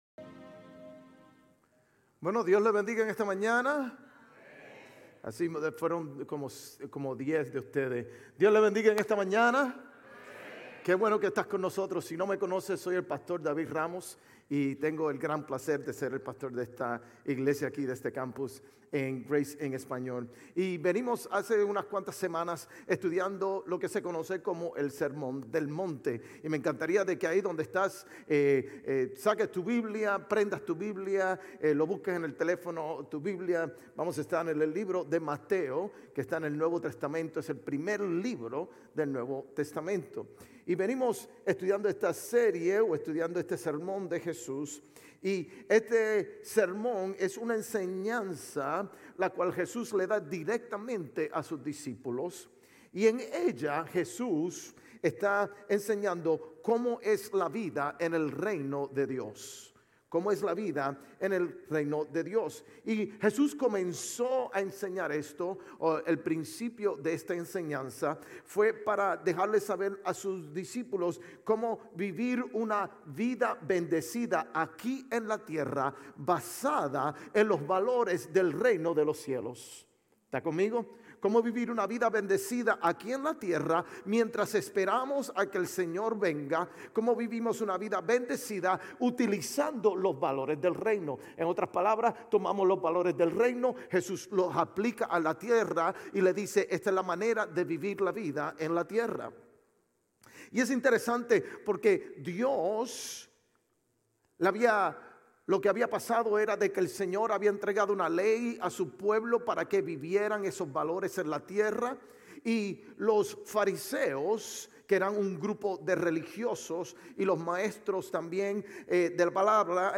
Sermones Grace Español 2_16 Grace Español Campus Feb 17 2025 | 00:38:55 1x 00:00 / 00:38:55 Subscribe Share RSS Feed Share Link Embed